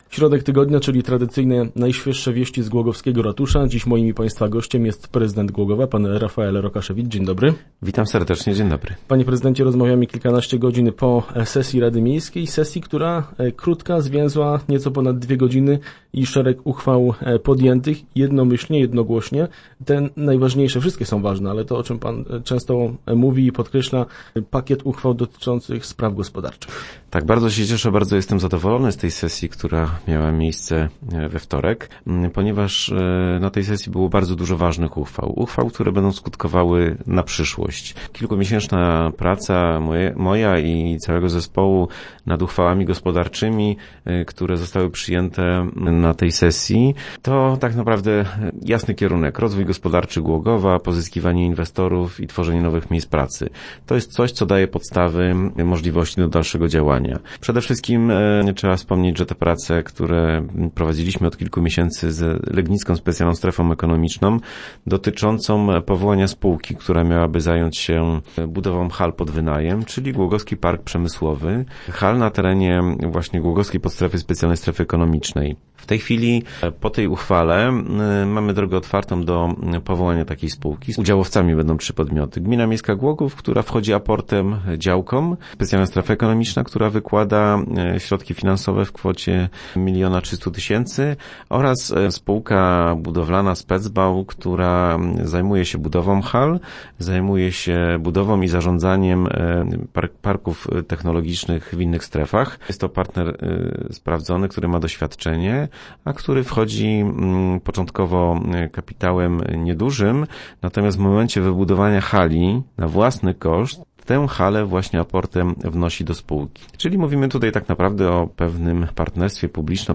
0610_rokasz_re.jpgNa temat wtorkowej sesji rady miasta oraz podjętych na niej uchwał rozmawialiśmy podczas środowej wizyty w studiu z prezydentem Głogowa Rafaelem Rokaszewiczem.